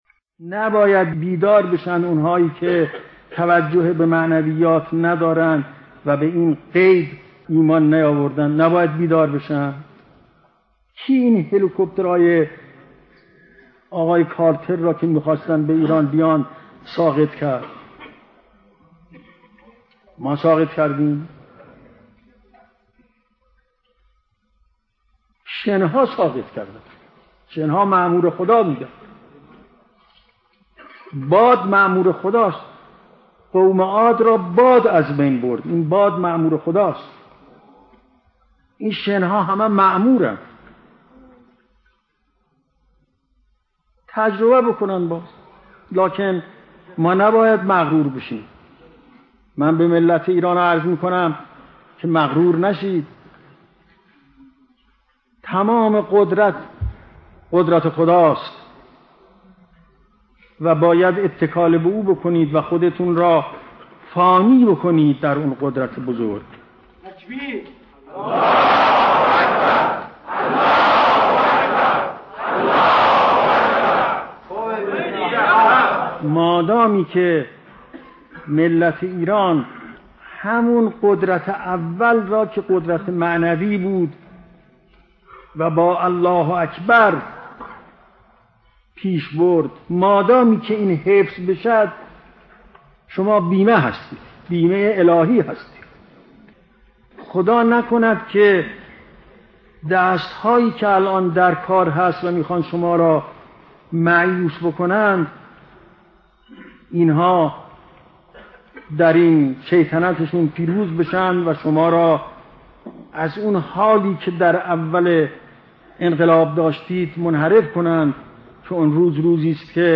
فرازی از سخنان حضرت امام خمینی (ره) در باب واقعه طبس